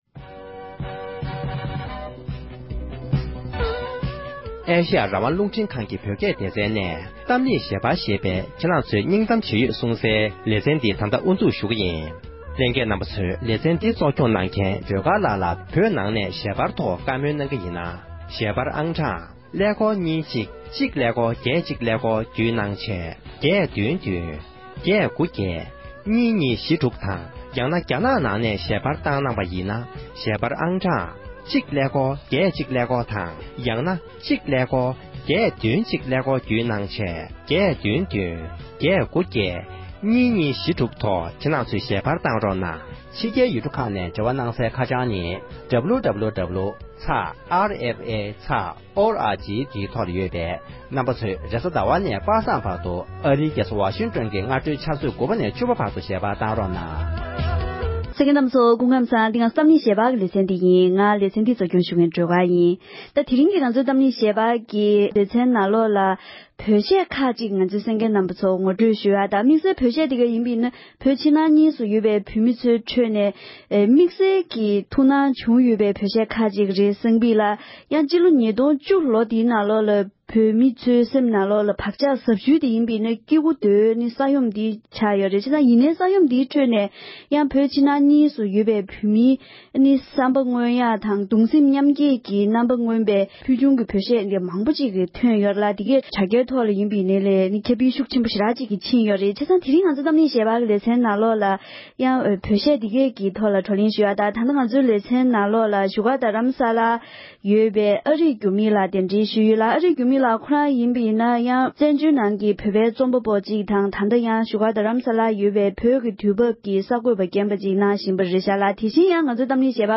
འབྲེལ་ཡོད་མི་སྣ་དང་བགྲོ་གླེང་ཞུས་པ་ཞིག་གསན་རོགས༎